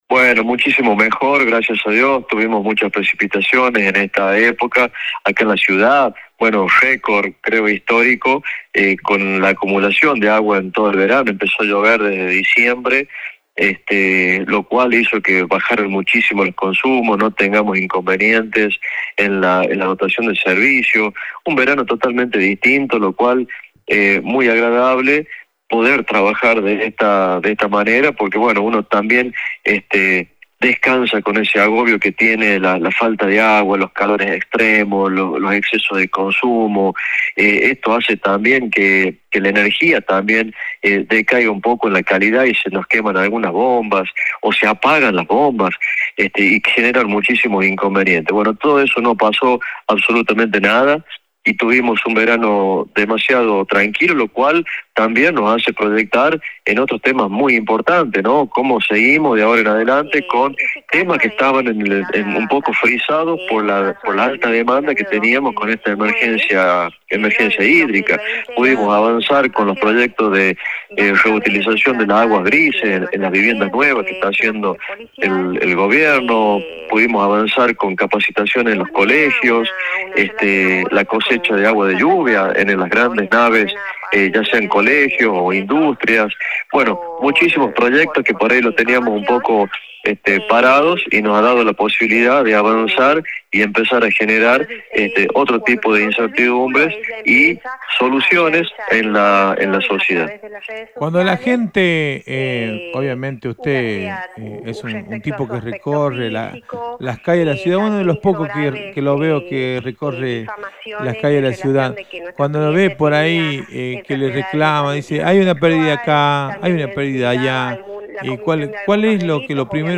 La entrevista subrayó la responsabilidad compartida entre el organismo y la comunidad en la gestión de un elemento que es el motor de la sociedad.